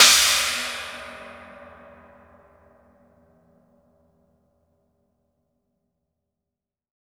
Crashes & Cymbals
20inch-china.wav